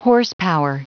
Prononciation du mot horsepower en anglais (fichier audio)
Prononciation du mot : horsepower